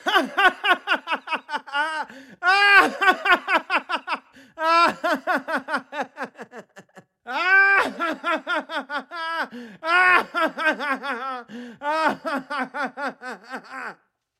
描述：一个男人的笑声从我的雅马哈PSR
Tag: 令人毛骨悚然 邪恶的笑 恐怖 人笑